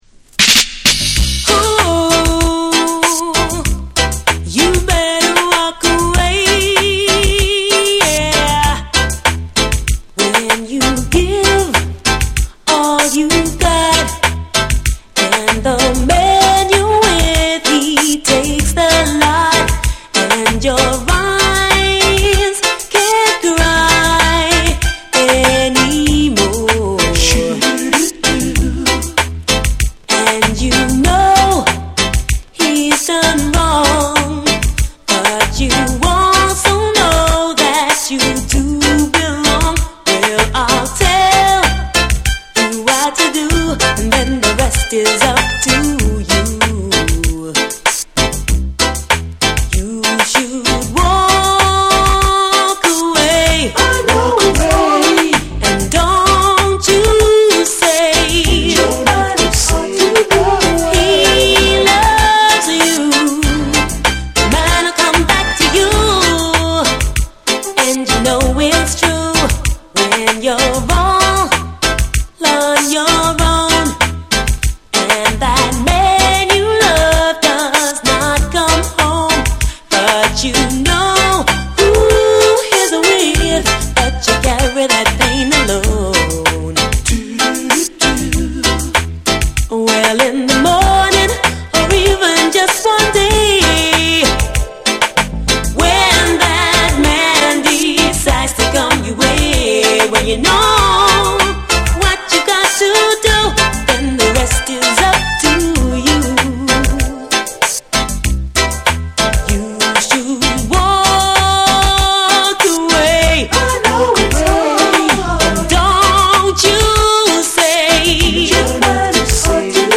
UKラヴァーズ・シーンを代表する女性シンガー